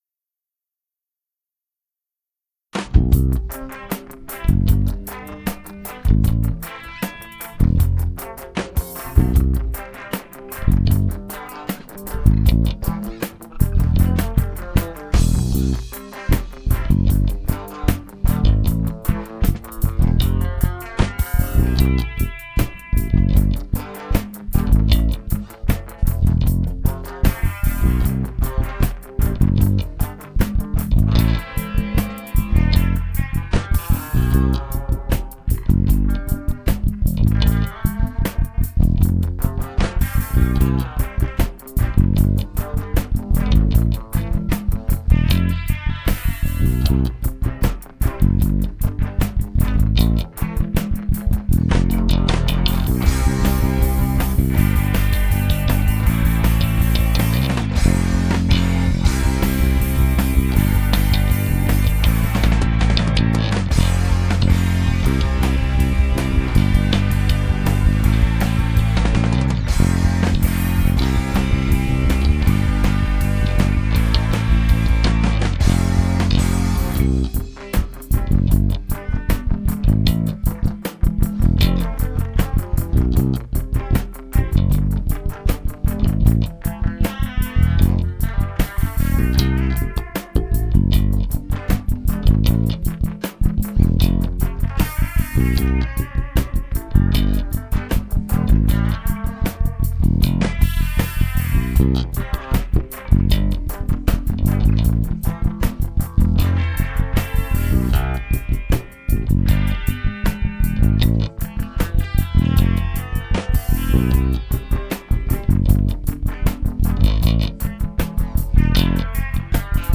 (instrumental)
Cover / Bass only